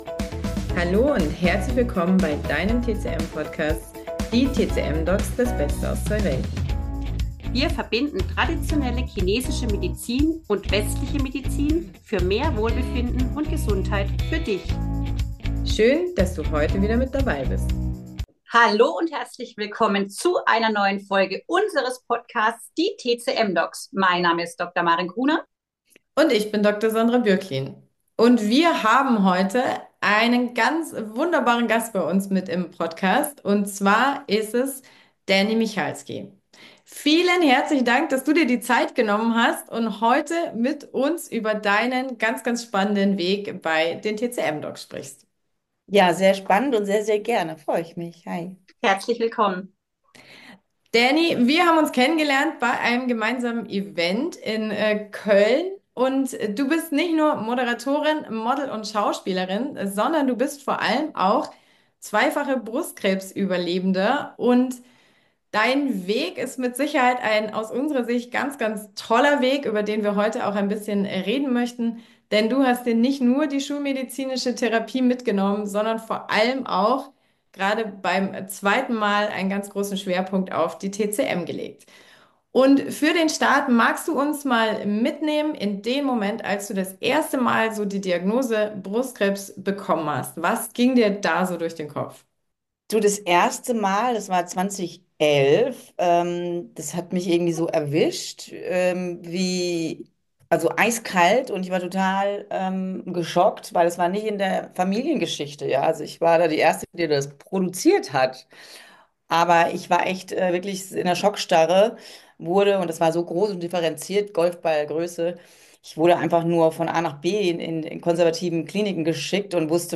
In der heutigen Folge von Die TCM-Docs durften wir einen ganz besonderen Gast begrüßen: Dany Michalski – bekannte Moderatorin, Model, Schauspielerin und zweifache Brustkrebs-Überlebende.